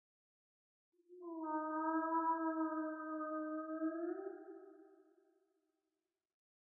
Whale Efeito Sonoro: Soundboard Botão
Whale Botão de Som